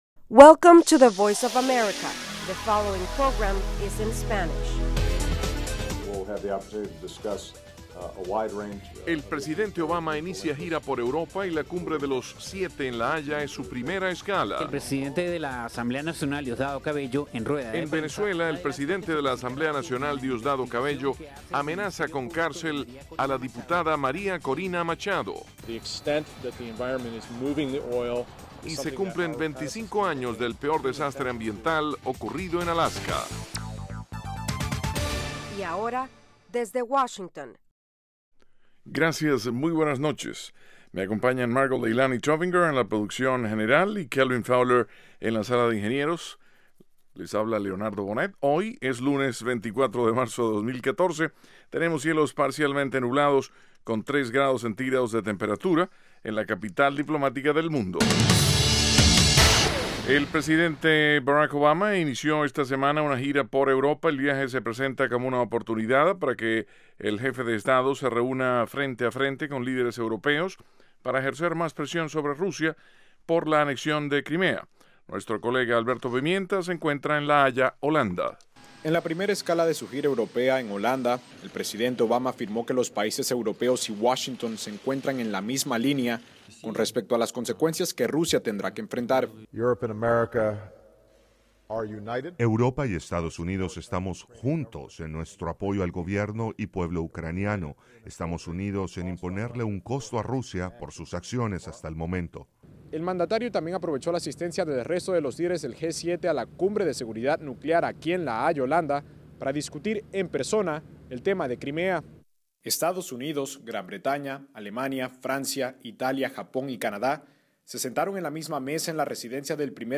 Con entrevistas a líderes políticos, nacionales y extranjeros, Desde Washington ofrece las últimas noticias sobre los acontecimientos que interesan a nuestra audiencia. El programa se transmite de lunes a viernes de 8:00 p.m. a 8:30 p.m. (hora de Washington).